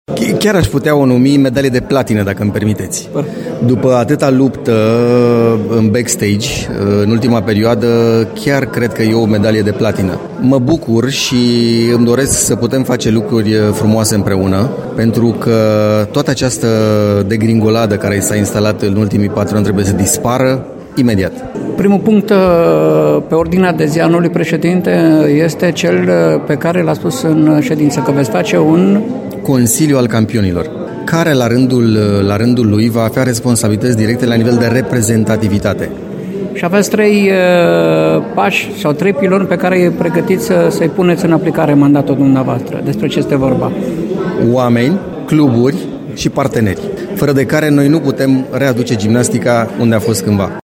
a vorbit cu Ioan Suciu, care compară câștigarea alegerilor cu câștigarea unei medalii: